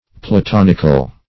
Platonic \Pla*ton"ic\, Platonical \Pla*ton"ic*al\, a. [L.